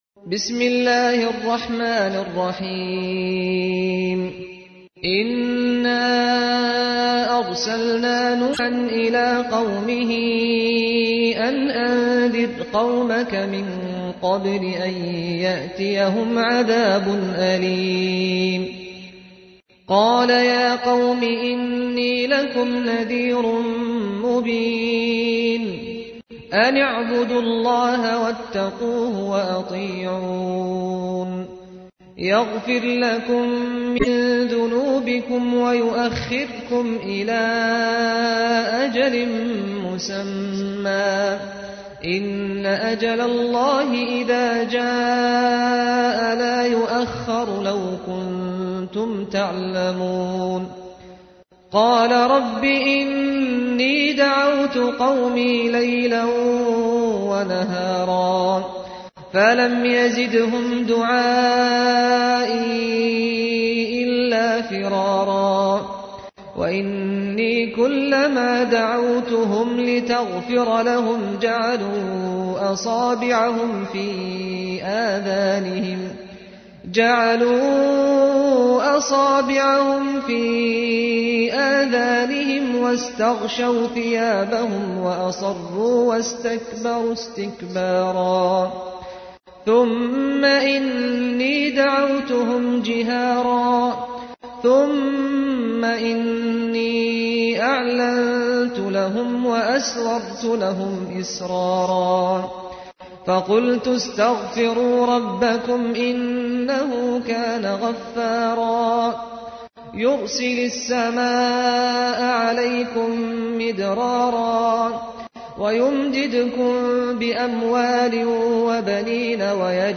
تحميل : 71. سورة نوح / القارئ سعد الغامدي / القرآن الكريم / موقع يا حسين